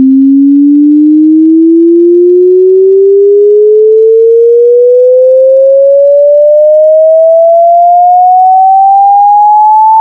[FFmpeg-user] hearing higher frequencies than expected from generated audio using aevalsrc
When I perform this command to generate what I expect should be a sine wave
sweeping from 262 hZ
Stream #0:0: Audio: pcm_f64le, 44100 Hz, mono, dbl, 2822 kb/s
octave higher than I expected.